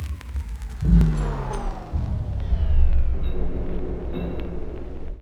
Vinyl_Tone_Layer_04.wav